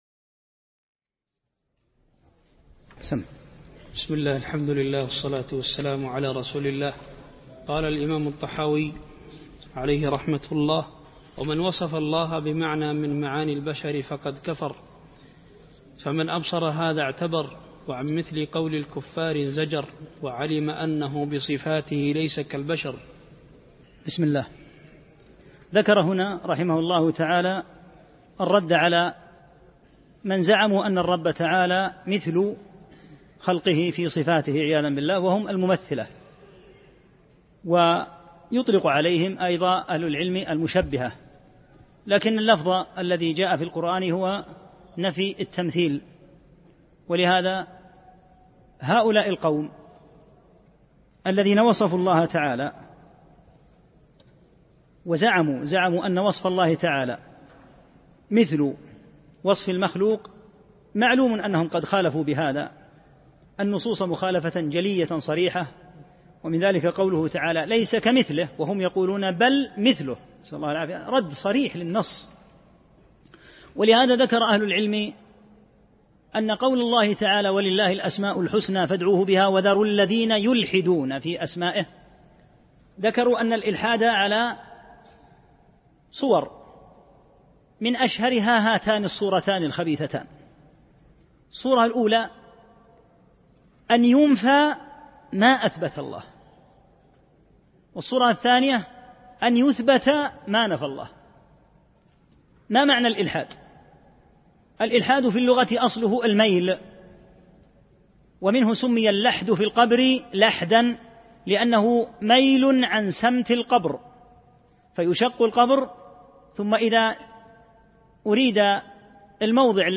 2 - الدرس الثاني